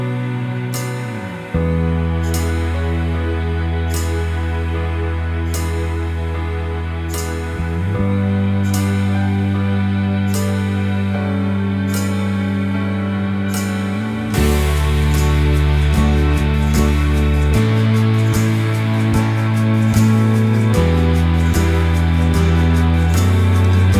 no Backing Vocals Indie / Alternative 4:29 Buy £1.50